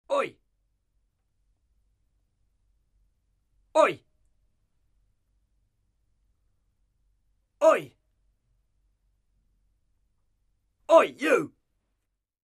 Звуки ой
Мужчина громко кричит ой